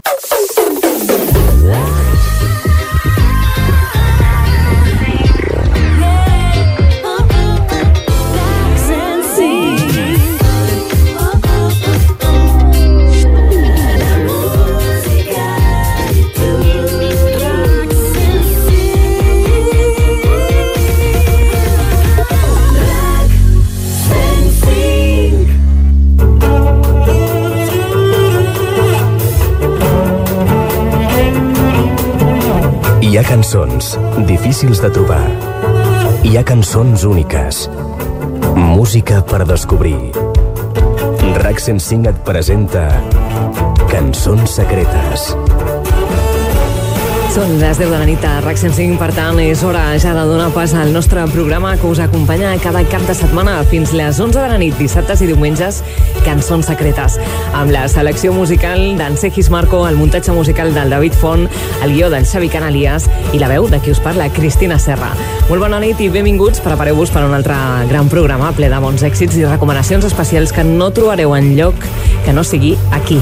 Indicatiu emissora. Careta del programa. Hora, equip i presentació.
Musical
FM